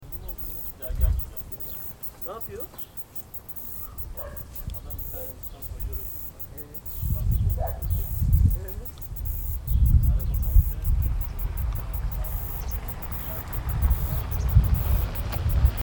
Cicadetta tibialis (Panzer, 1798) La Cigale à tibias armés
Cymbalisation